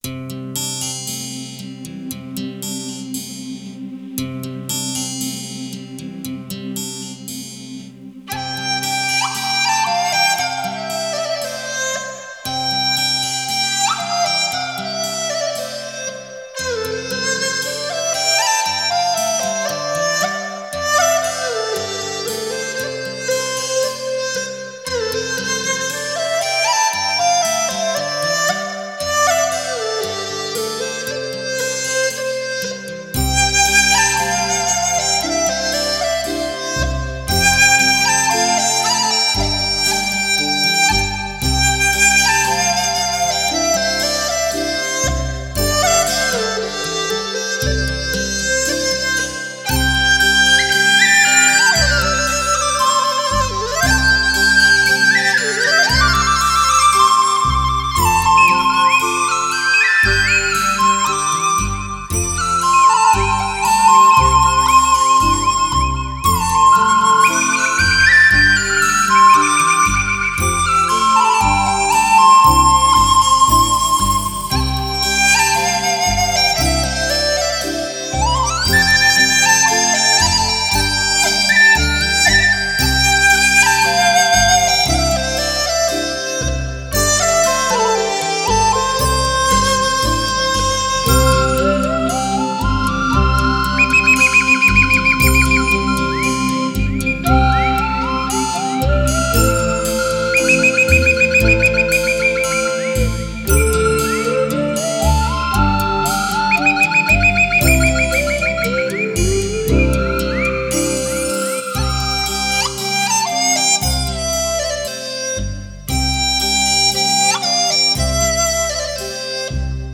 以曲笛、梆笛、新笛、口笛、低音笛、木笛为主，辅以领奏、轮奏、二重奏、三重奏加之背景技巧融为一体的全新演绎手法。